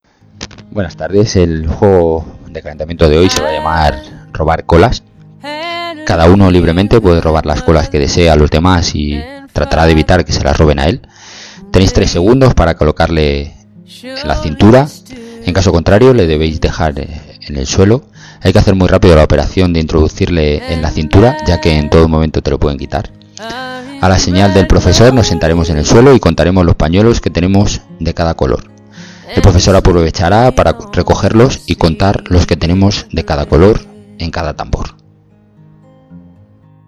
narración